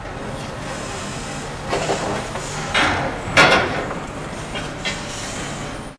Factory Ambience
A busy factory floor with multiple machines running, metal clanking, and distant workers
factory-ambience.mp3